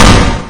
bang.ogg